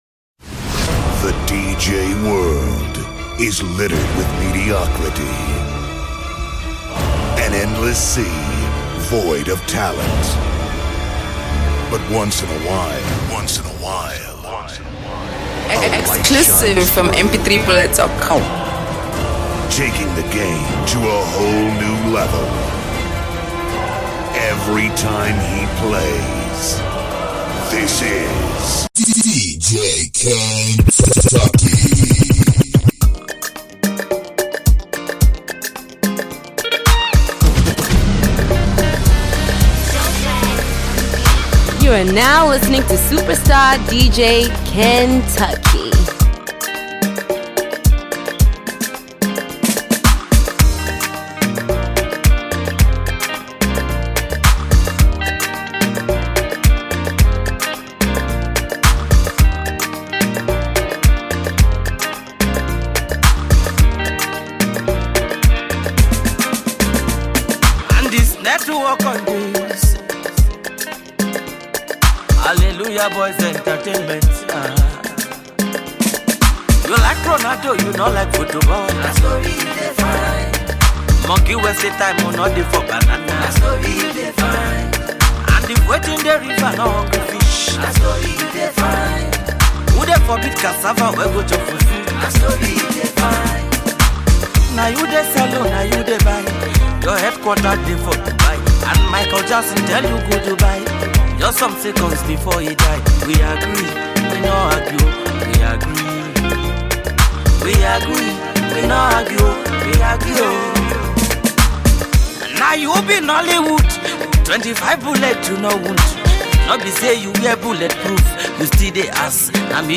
afrobeat tunes